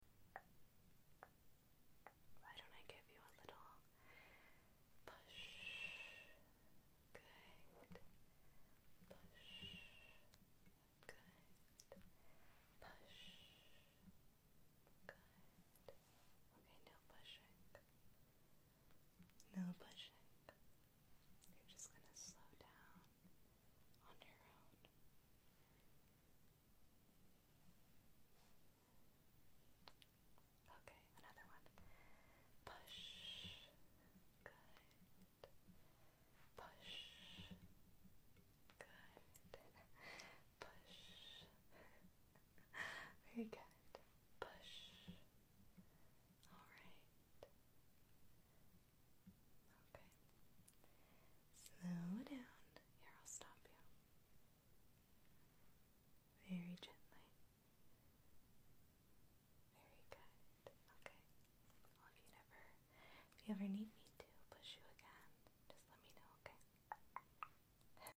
Some ASMR camera movements for sound effects free download